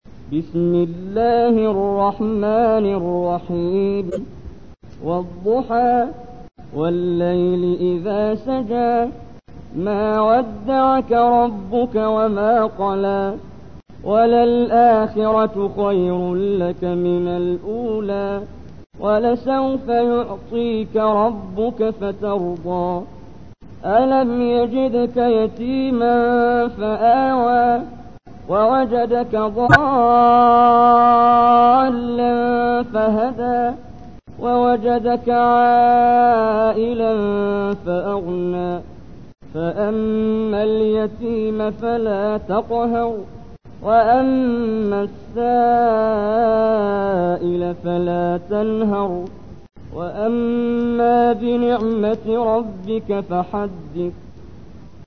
تحميل : 93. سورة الضحى / القارئ محمد جبريل / القرآن الكريم / موقع يا حسين